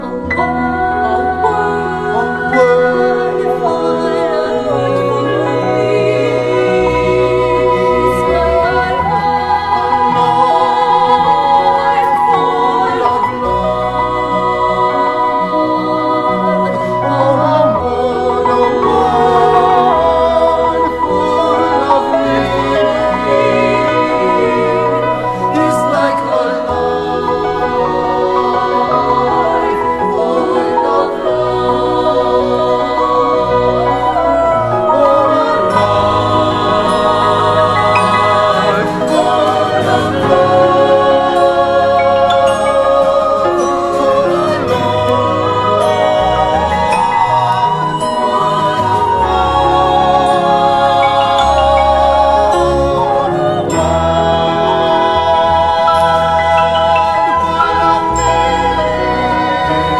¥4,980 (税込) ROCK / SOFTROCK.